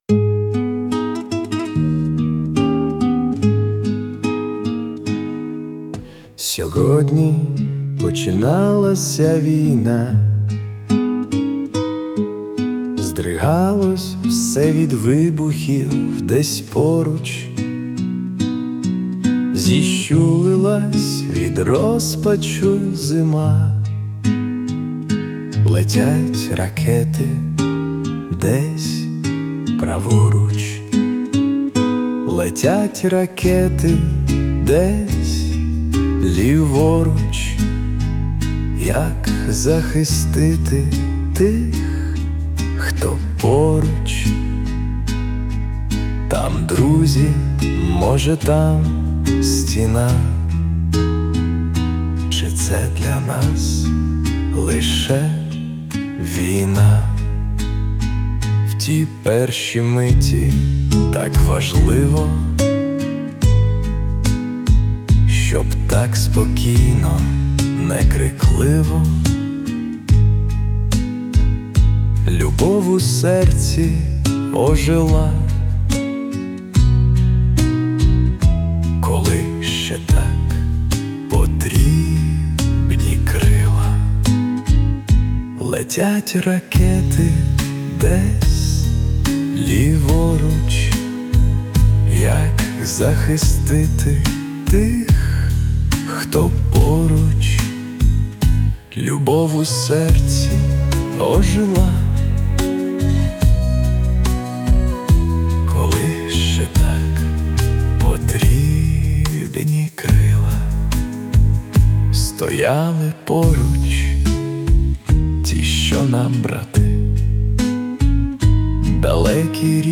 Варіант пісні
Присутня допомога SUNO
вийшов досить оптимістичним ...
злість + оптимізм !!!